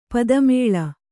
♪ pada mēḷa